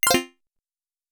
lock.wav